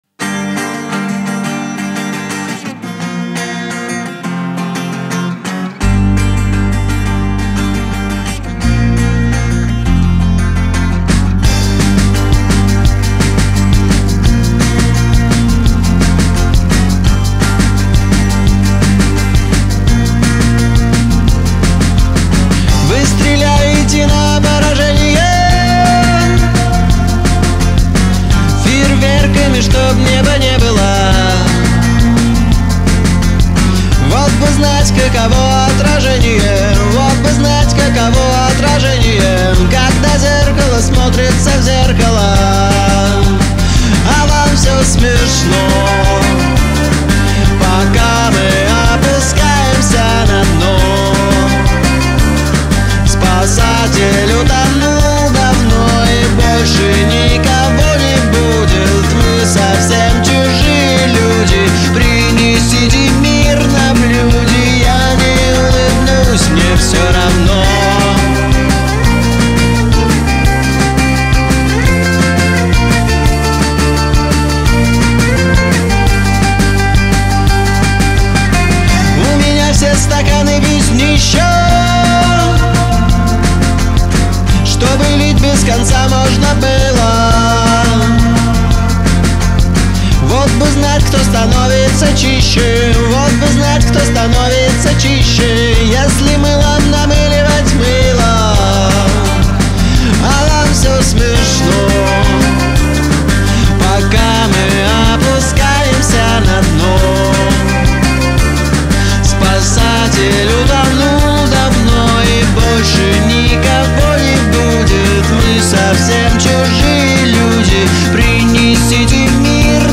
который сочетает элементы рок-музыки и остроумного текста